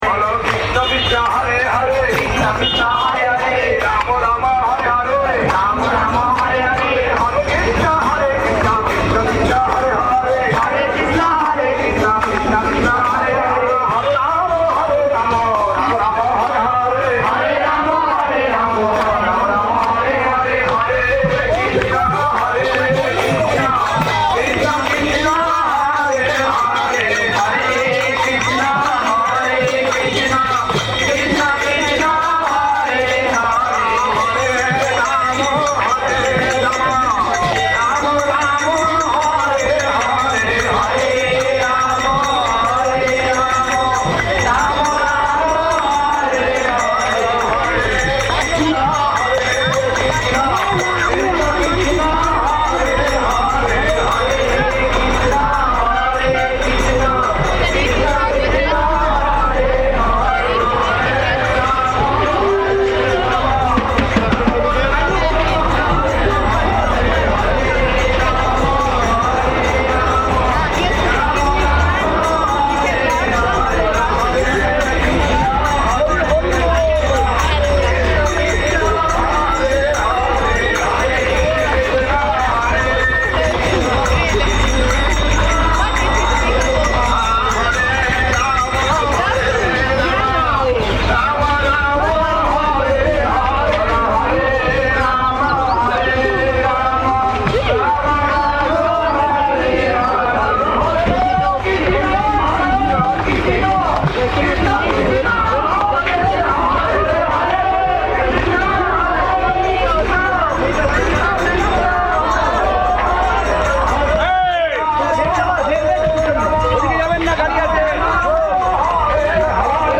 Kirttan
Place: SCSMath Nabadwip